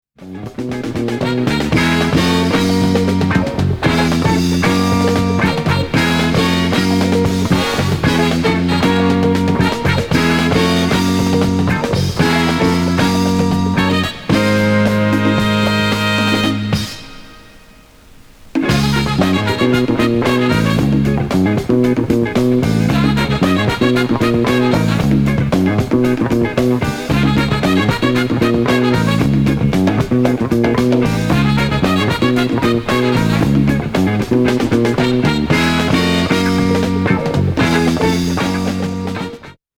間違いなく悶絶級のド・ファンク